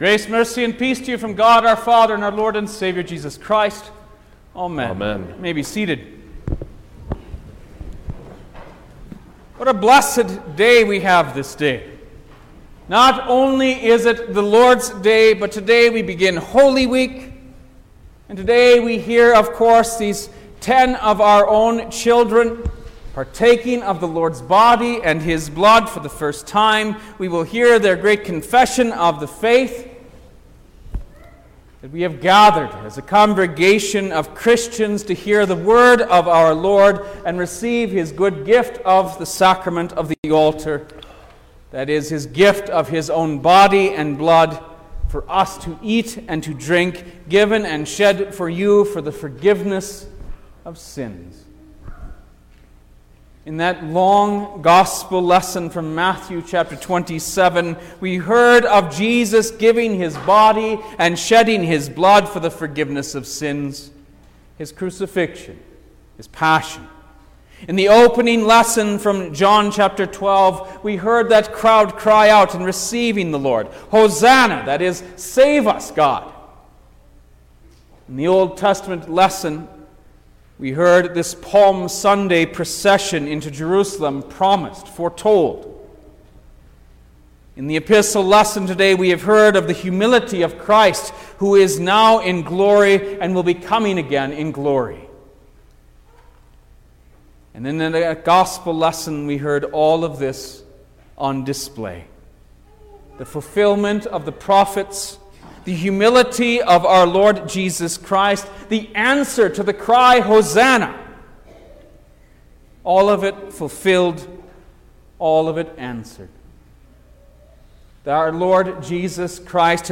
April-2_2023_Palm-Sunday_Sermon-Stereo.mp3